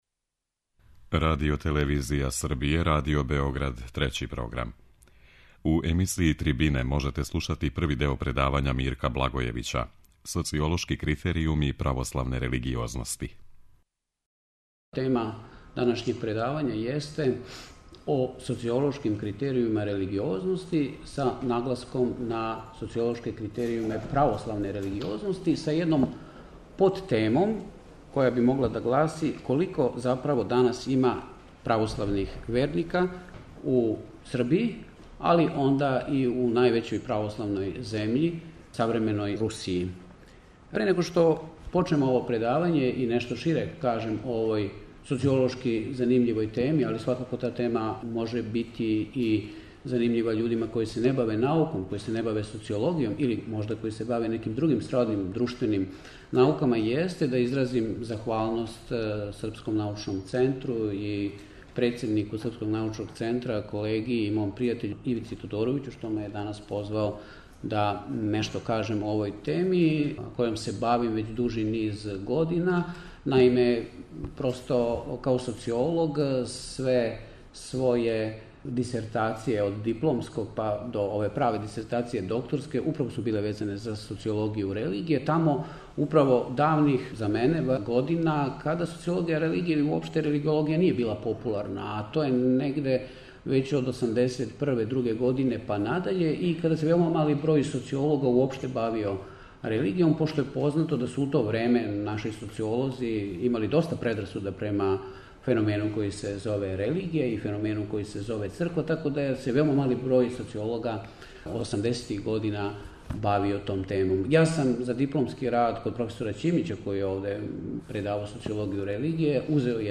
преузми : 21.32 MB Трибине и Научни скупови Autor: Редакција Преносимо излагања са научних конференција и трибина.